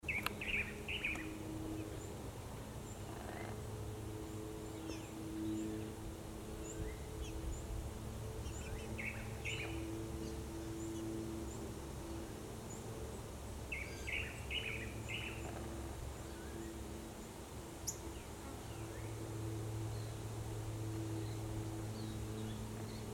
Tangará (Chiroxiphia caudata)
Nome em Inglês: Blue Manakin
Fase da vida: Adulto
Localidade ou área protegida: Parque Federal Campo San Juan
Condição: Selvagem
Certeza: Gravado Vocal